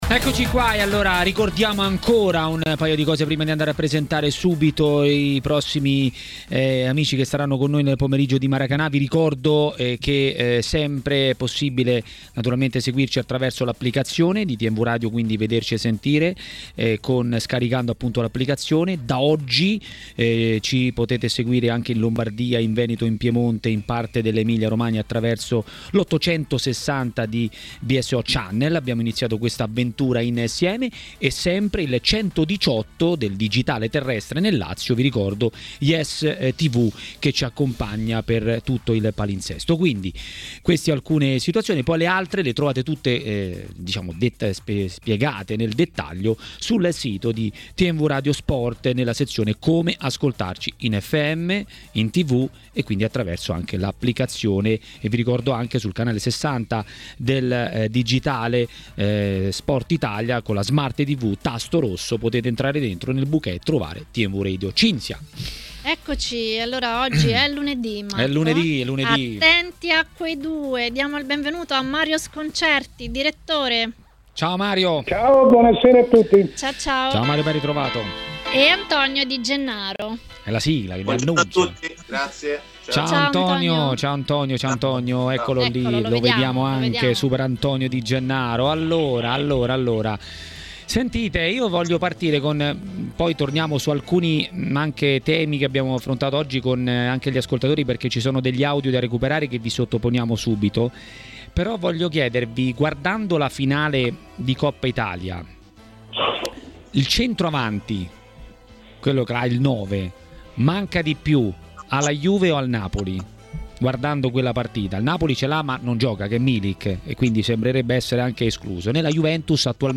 A parlare dell'attualità del calcio italiano a TMW Radio, durante Maracanà, è stato il direttore Mario Sconcerti.